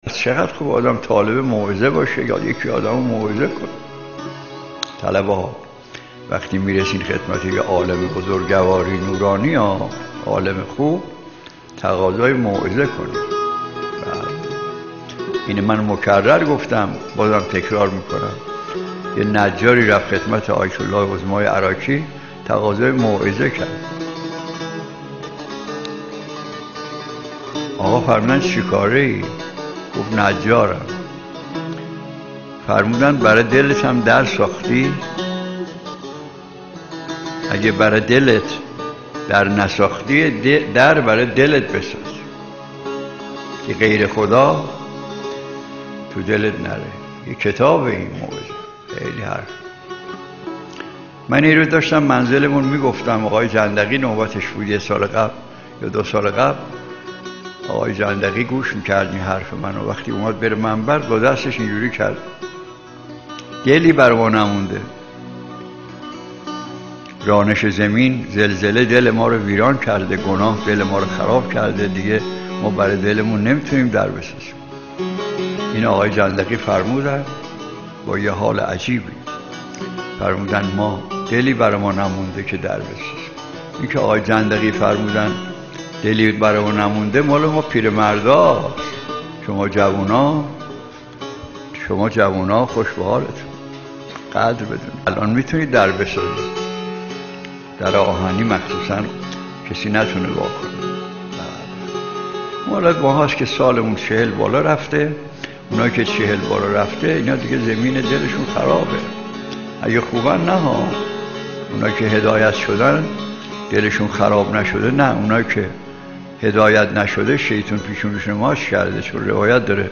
موعظه.mp3